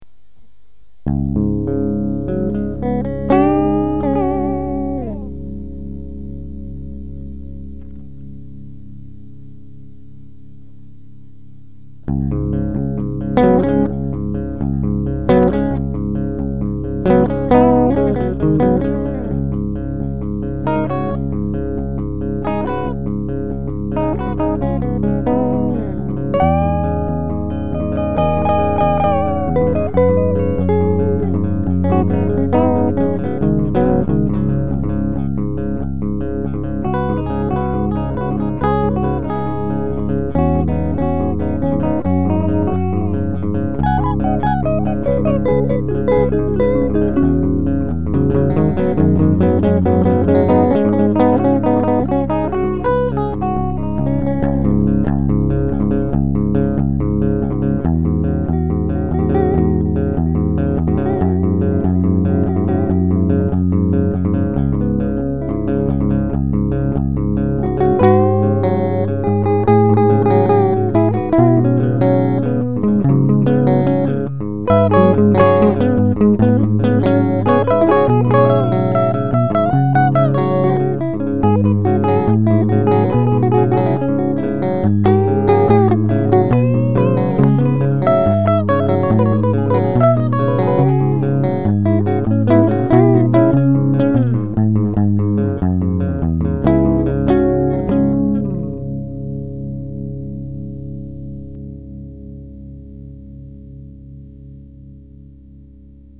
"Gates" and "Psychomachy" are two of many parts of a Dropped D tuning epic song!